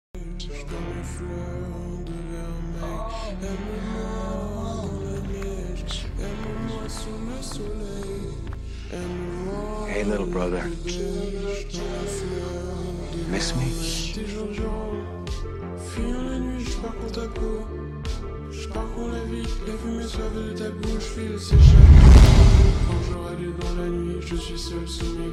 slowed reverb